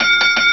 wecker.wav